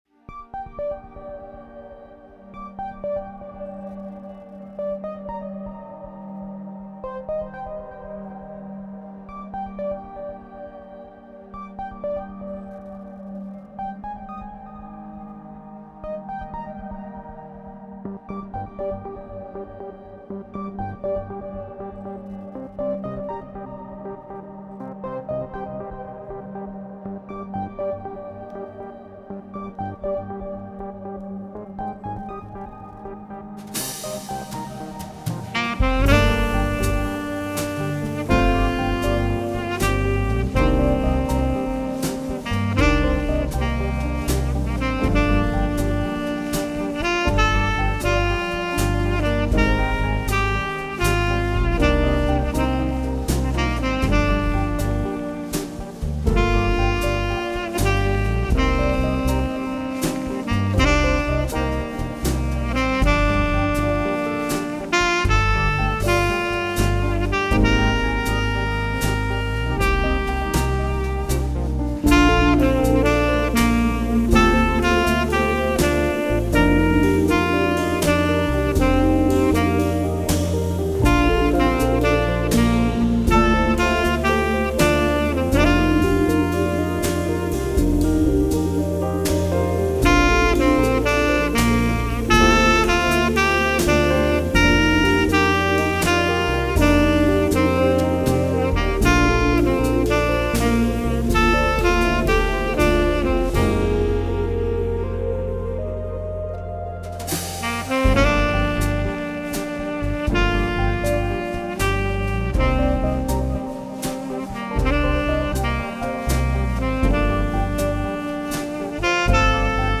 Is it a jazz album?
trumpet
tenor sax
soprano sax
drums